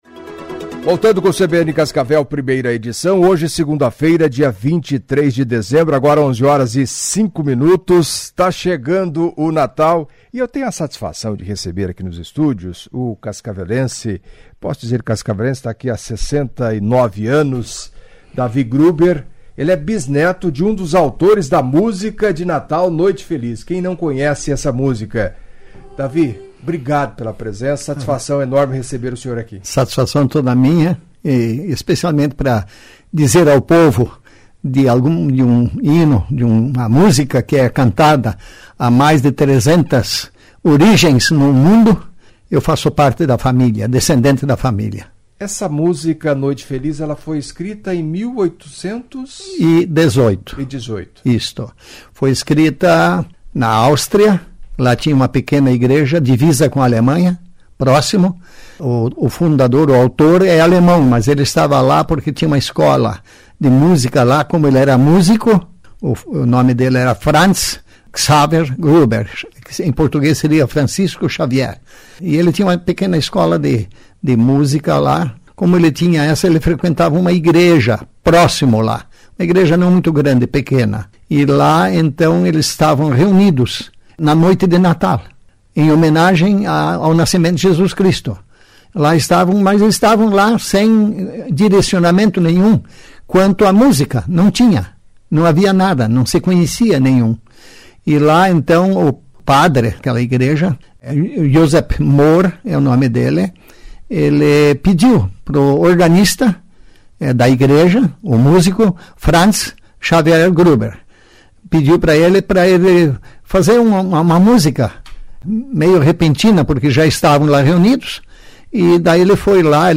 Em entrevista à CBN nesta segunda-feira